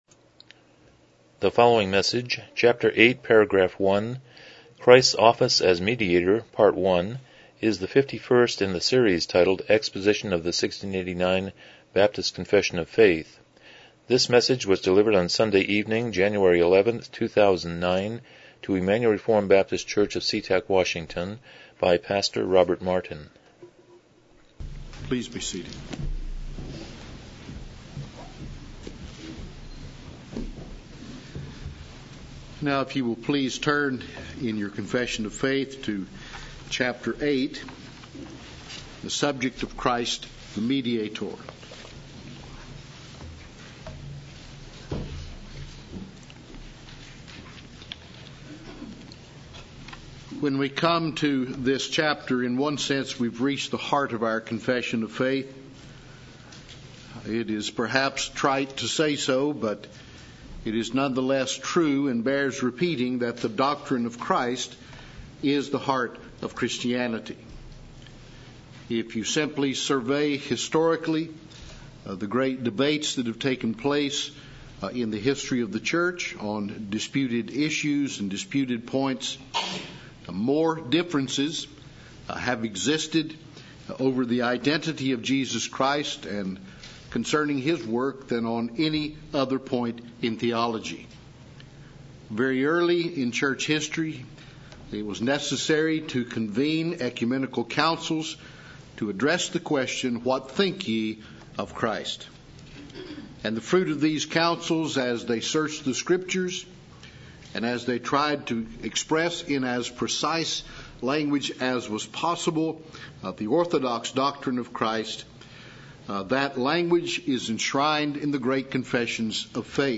1689 Confession of Faith Service Type: Evening Worship « 68 Romans 5:5-11 17 The Obligations of the Creation Bond